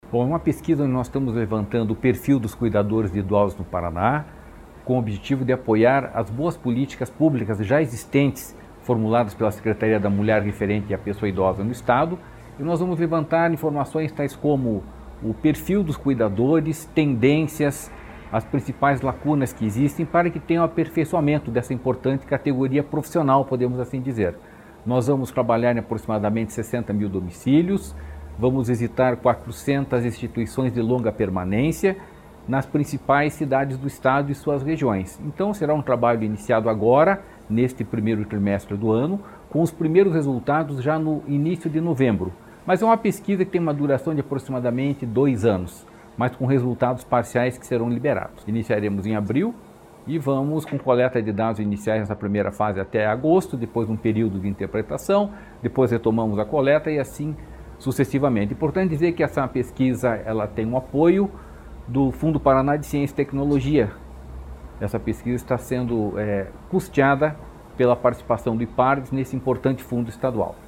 Sonora do diretor-presidente do Ipardes, Jorge Callado, sobre a pesquisa para mapear o perfil de cuidadores de idosos em todo o Paraná